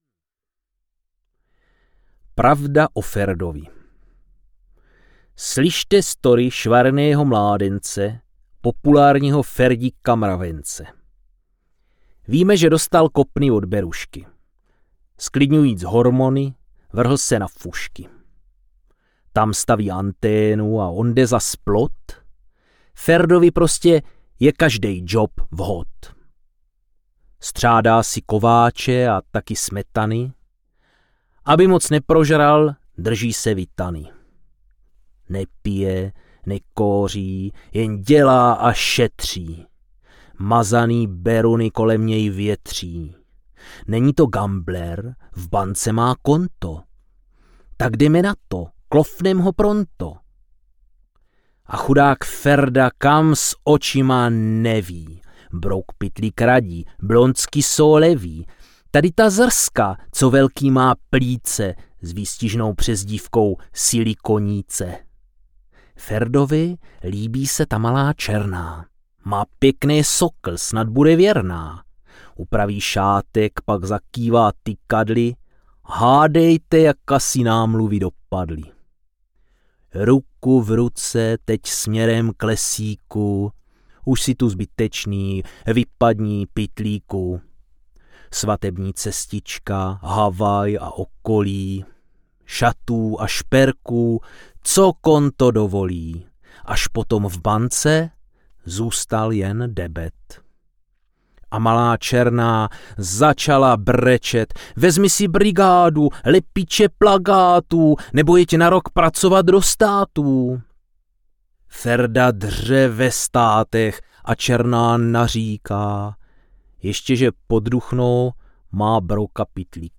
Bůh je z Brna audiokniha
Ukázka z knihy